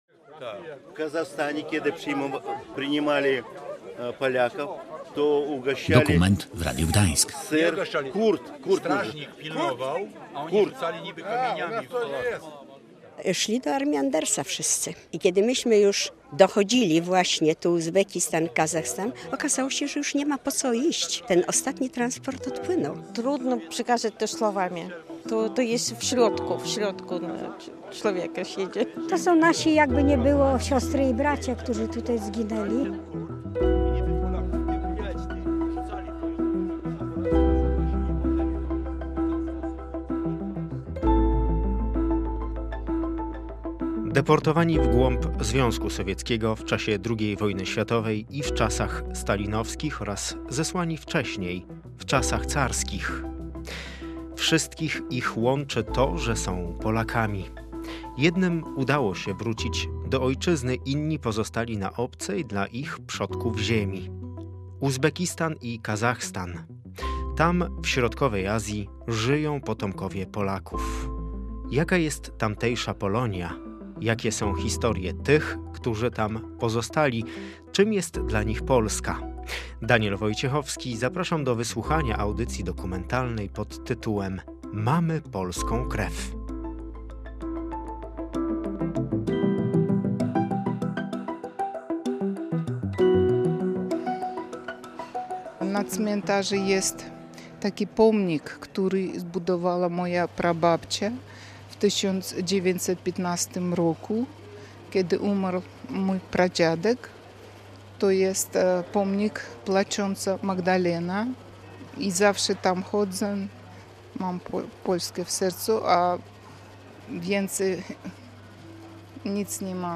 Historie uzbeckiej i kazachstańskiej Polonii znajdziesz w audycji dokumentalnej pod tytułem „Mamy polską krew”.